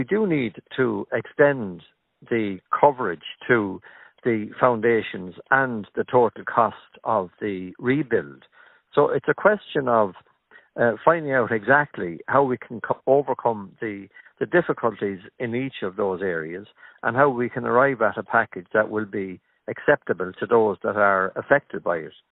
Committee Chair, Deputy John McGuinness, says they’re compiling a report to present to government, with recommendations on what should happen next: